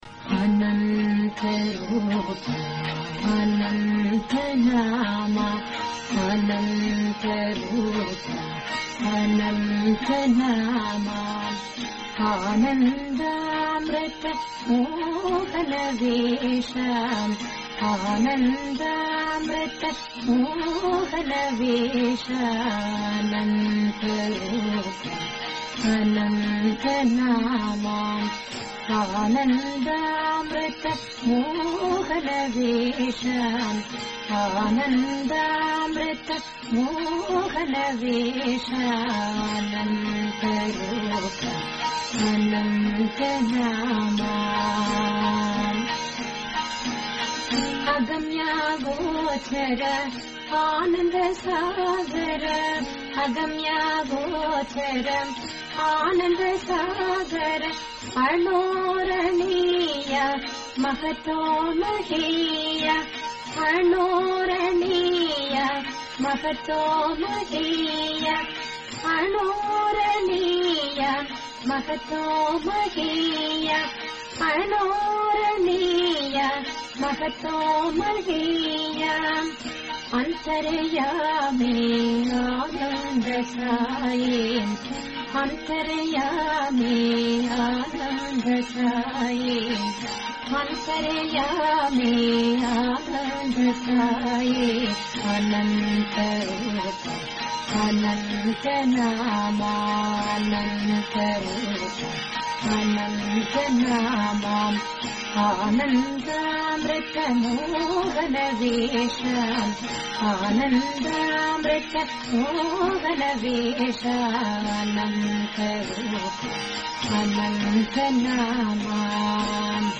Related Bhajan